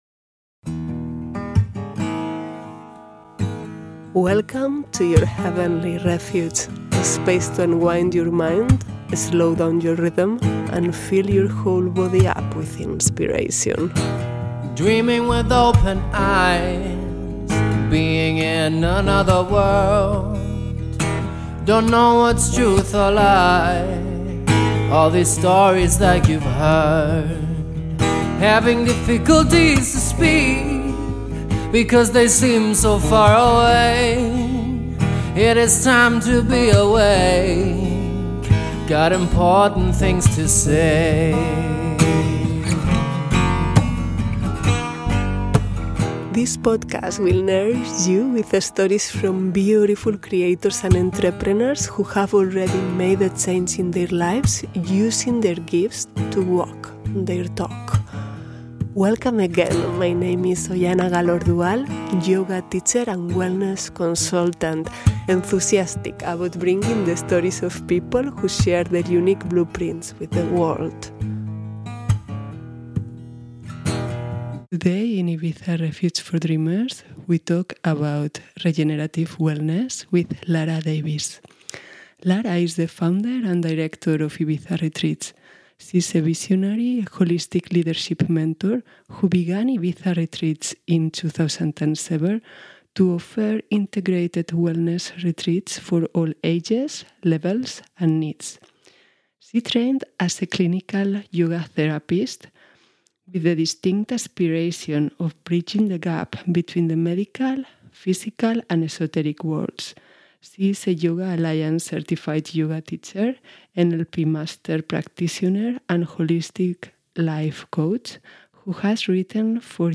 In this episode, you will listen to a conversation on;